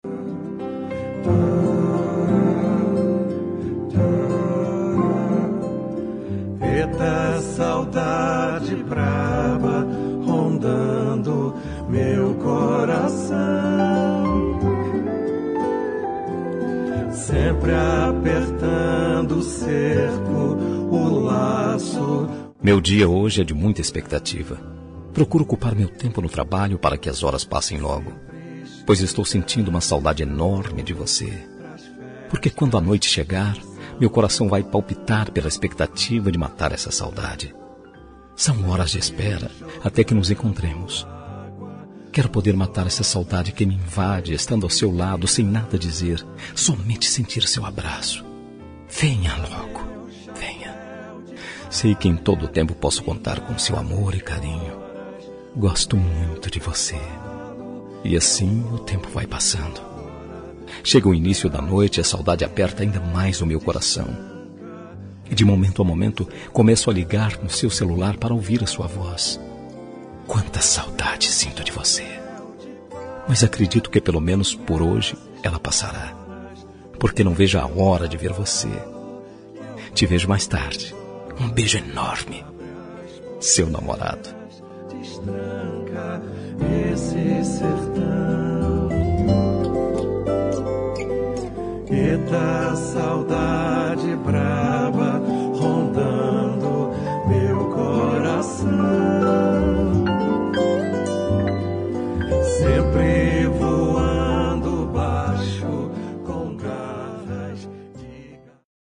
Telemensagem de Saudades – Voz Masculina – Cód: 453